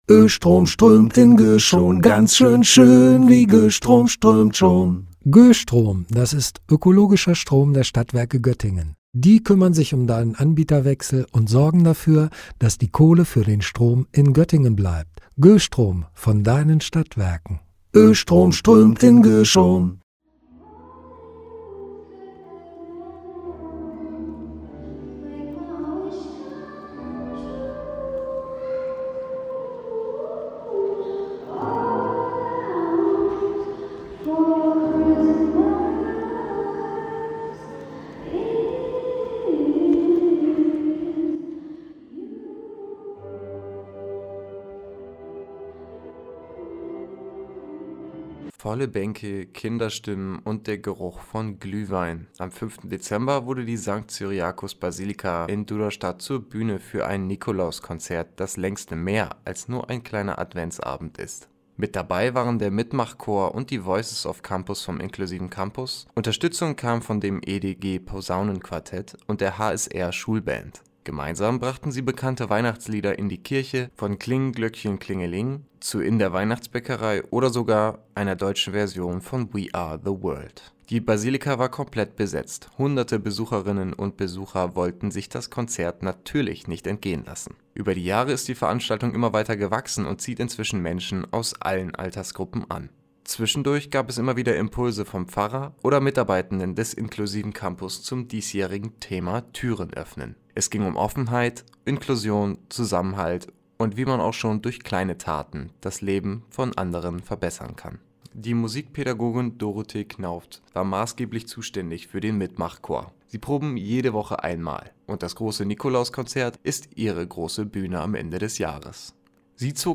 Volle Basilika, Kinderstimmen und echte Adventsstimmung. In Duderstadt hat das Nikolauskonzert in der St.-Cyriakus-Basilika wieder Hunderte Menschen angezogen. Mit dabei: inklusive Chöre, Schulband und Blechbläser.